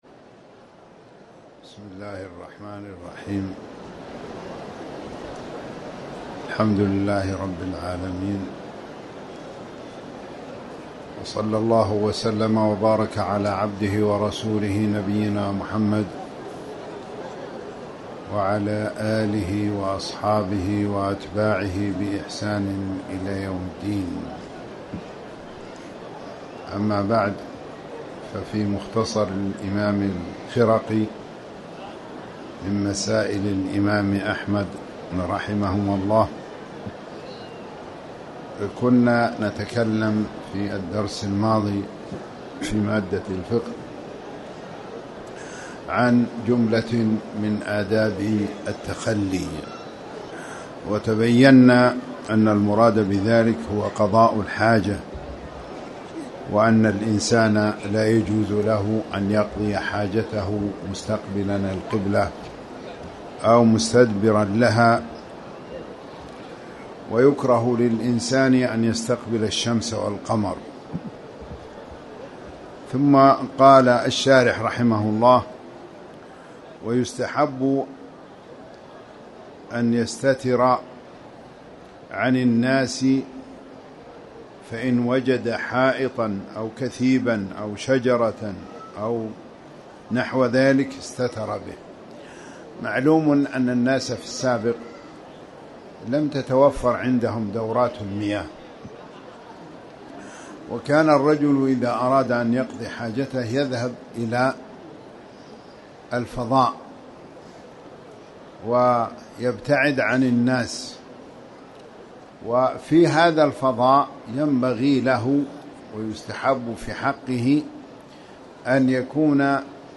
تاريخ النشر ٢ شعبان ١٤٣٩ هـ المكان: المسجد الحرام الشيخ